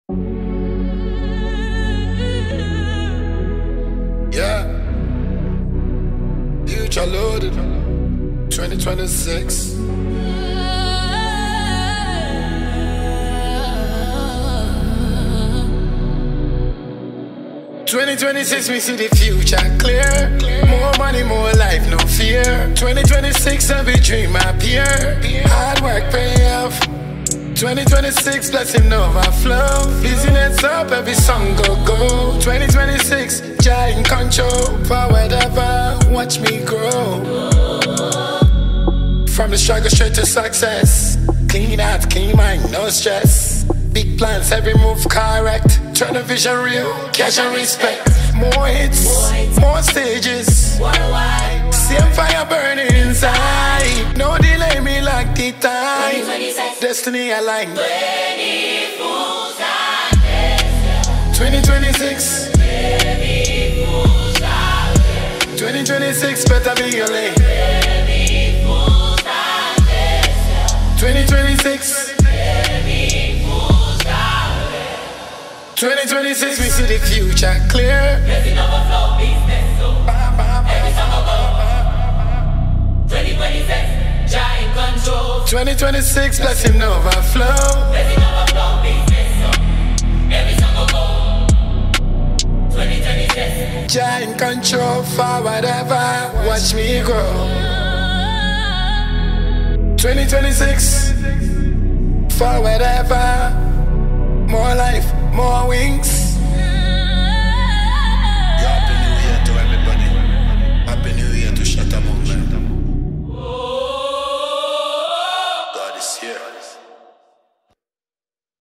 Ghanaian dancehall record
• Genre: Dancehall / Afro-Dancehall